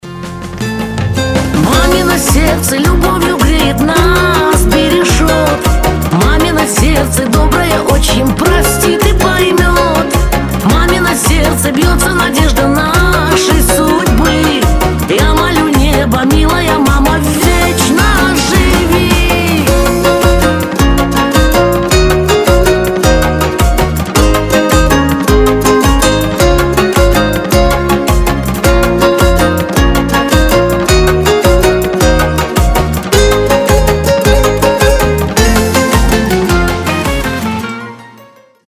• Качество: 320, Stereo
гитара
душевные
русский шансон
добрые